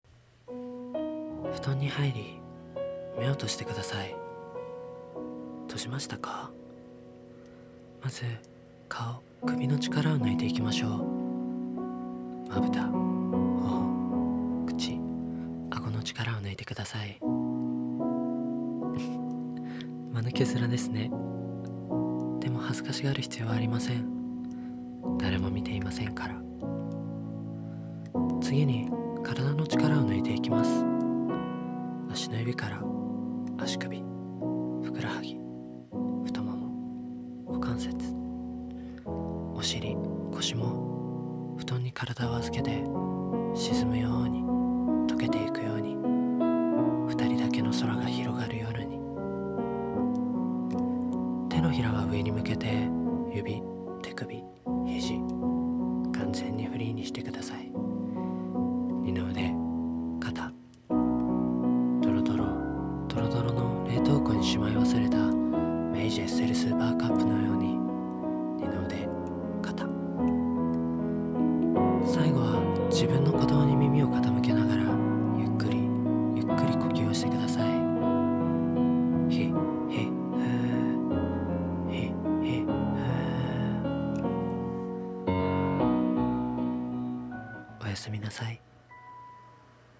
私的睡眠導入音声［台本］